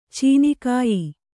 ♪ cīni kāyi